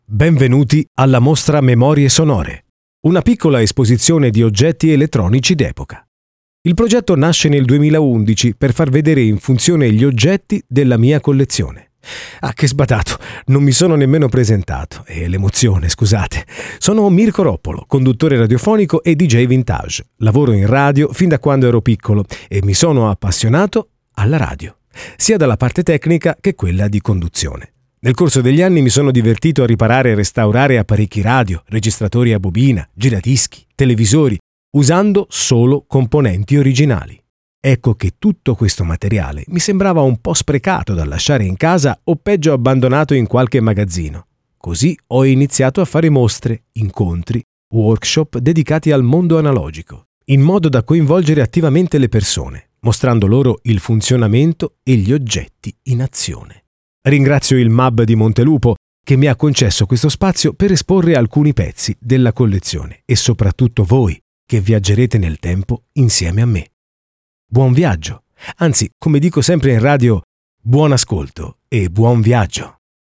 Ascolta l’audioguida della mostra al MMAB di Montelupo Fiorentino.